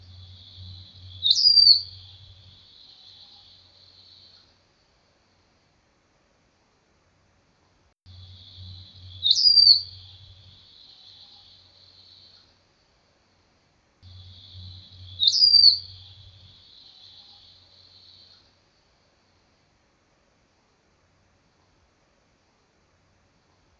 Agriornis murinus - Gaucho chico.wav